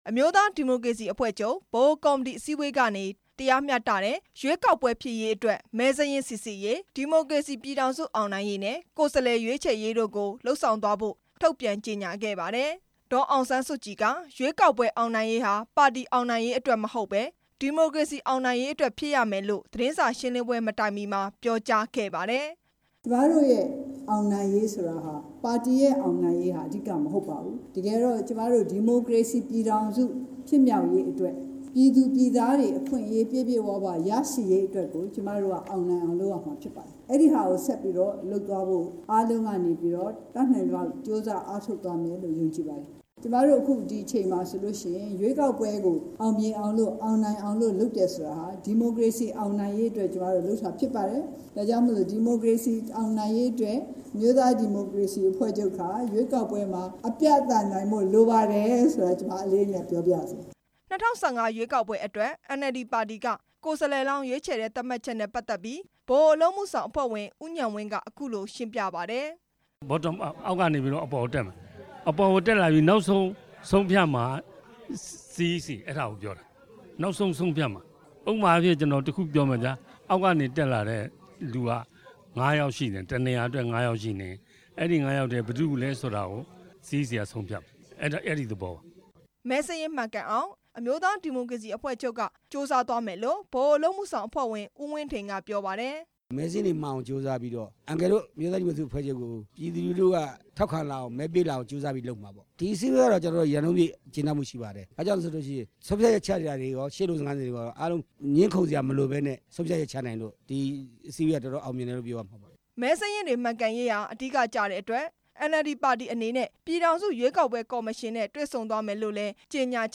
အမျိုးသားဒီမိုကရေစီအဖွဲ့ချုပ်ရဲ့ ၂ ရက်ကြာ ဗဟိုကော်မတီအစည်းအဝေးအပြီး ဒီနေ့ သတင်းစာ ရှင်းလင်းပွဲမတိုင် ခင်မှာ ပါတီဝင်တွေကို ပြောကြားတဲ့ အချိန်မှာ ဒေါ်အောင်ဆန်းစုကြည်က ပြောခဲ့တာပါ။